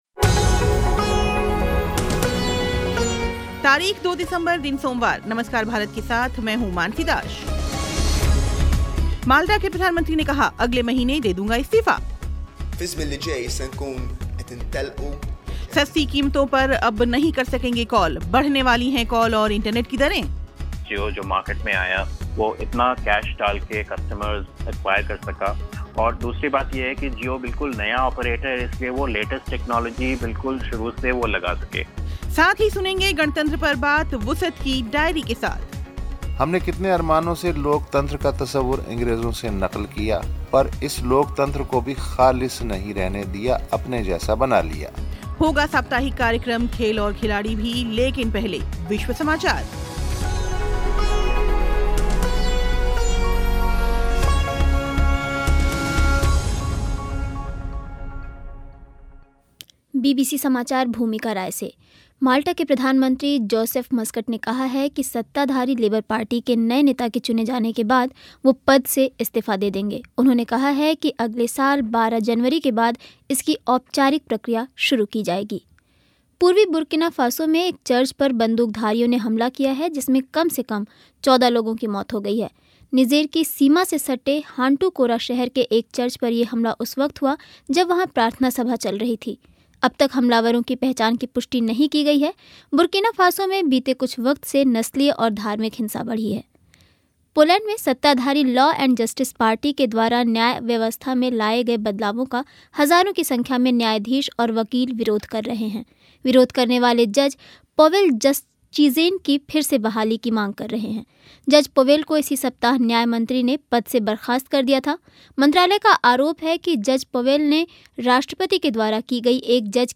झारखंड के पूर्व मुख्यमंत्री हेमंत सोरेन का साक्षात्कार
प्रेस रिव्यू